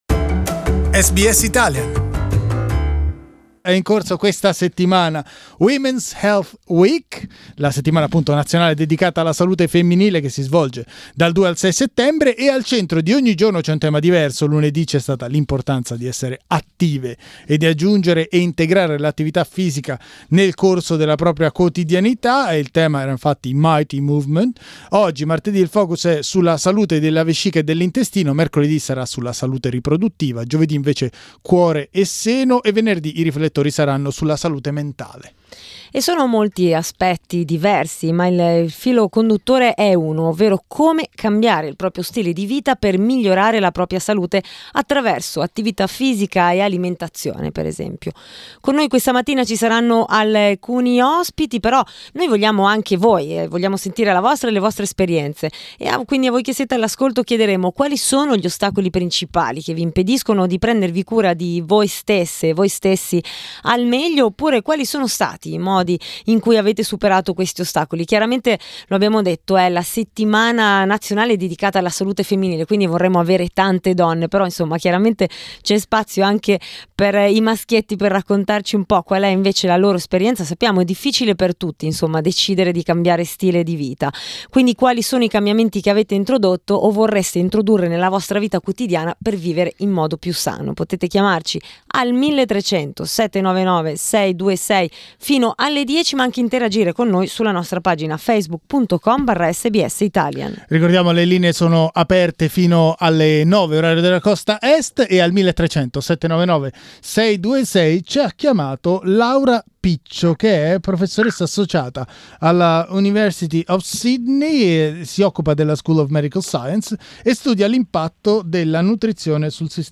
Women's Health Week is under way. We talked to two researchers about how changing our eating and exercise patterns can impact in a positive way our health.